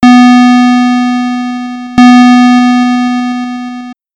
The following info and demonstration are useful for both .spc warriors and .smc mages (be warned they are a bit loud):
EXAMPLE 2 (square wave)
Both of these examples consist of a single held note across the above two bars of volume event data.
Fades of volume or panning will create slight crackling noise, and this noise becomes much more perceptible in the event of a huge, immediate shift.
c700_volumecrackle_square.mp3